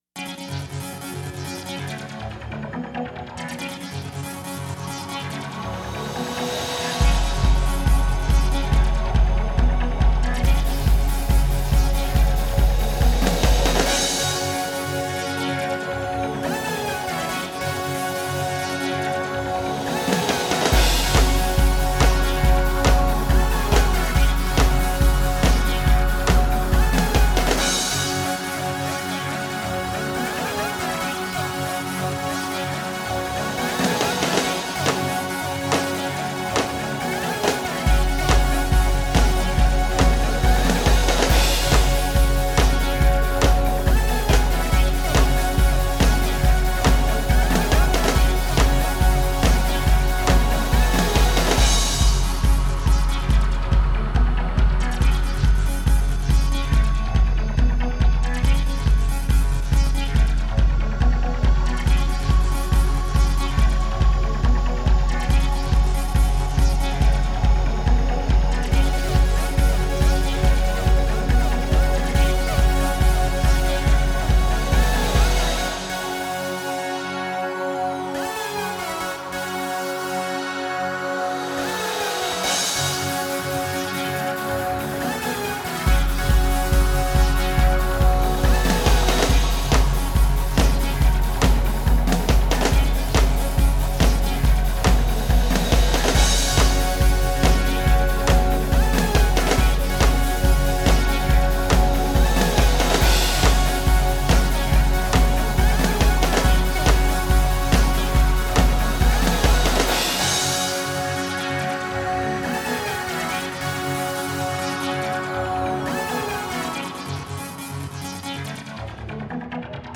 High Score Rave (with voice clips)